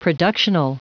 Prononciation du mot productional en anglais (fichier audio)
Prononciation du mot : productional